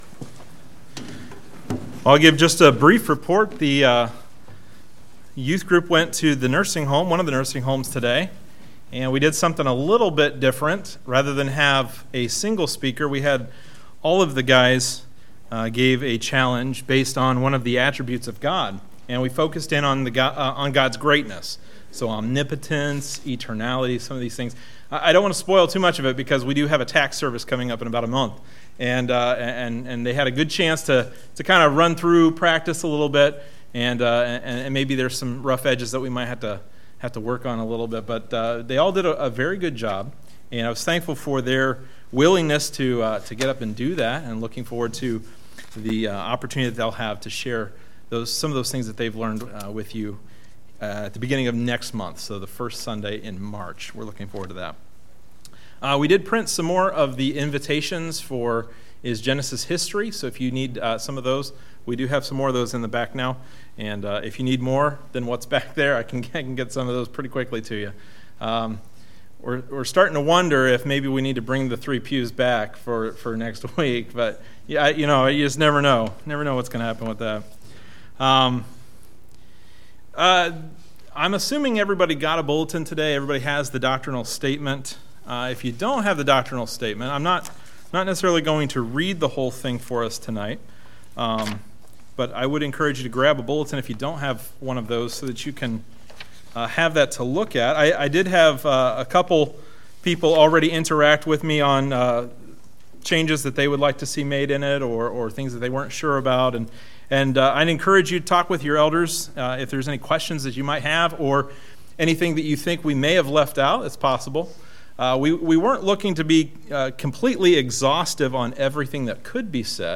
Service Sunday Evening